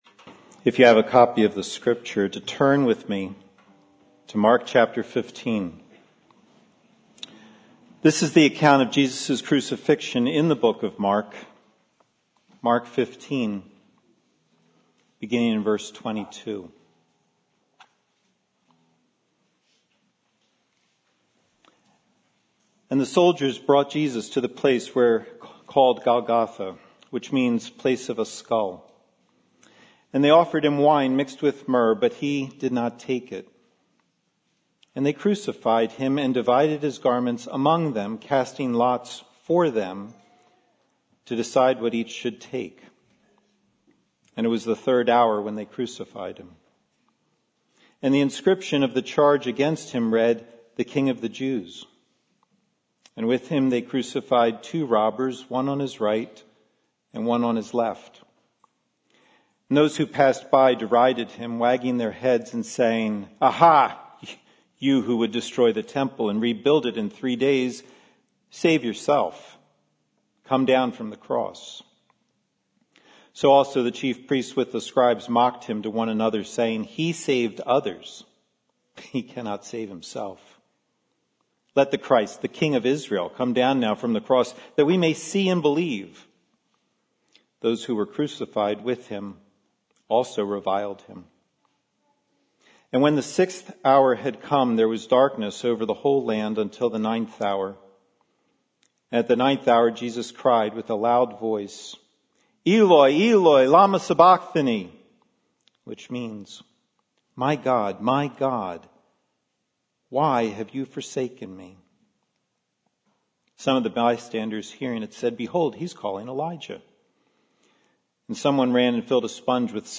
This is the RSS feed for Sunday sermons from New Life Presbyterian Church, Glenside, PA.